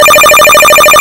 • ▲ ▼ Wie wär's mit retro-style? 3 Töne (Rechteck) kurz hintereinander (600 Hz, 1200 Hz, 2400 Hz) für je ca. 25ms gibt nen hübschen commodore-like Sound...
bell.mp3